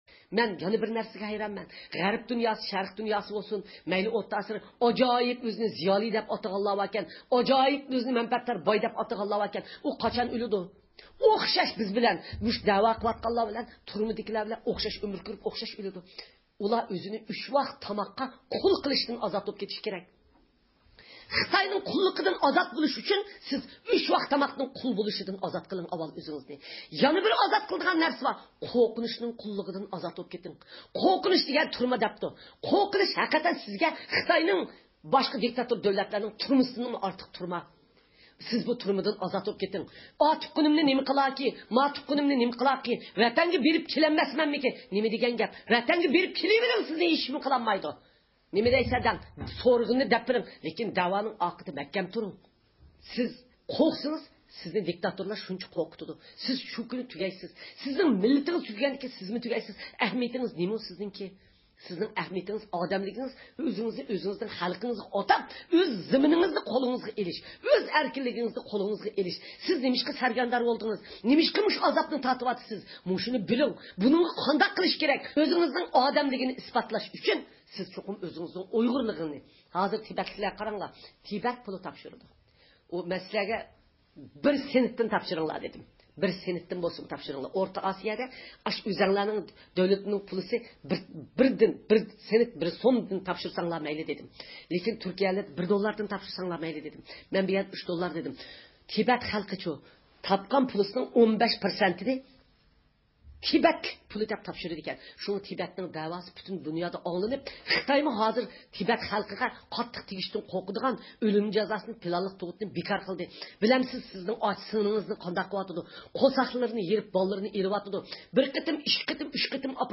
ئۇيغۇر مىللىي ھەركىتىنىڭ رەھبىرى، دۇنيا ئۇيغۇر قۇرۇلتىيىنىڭ رەئىسى رابىيە قادىر خانىم گېرمانىيىنىڭ ميۇنخېن شەھىرىدىكى زىيارىتى داۋامىدا شەرقى تۈركىستان ئىنفورماتسيۇن مەركىزى تەسىس قىلغان ئۇيغۇر تېلېۋىزىيىسىدە نوتۇق سۆزلىدى.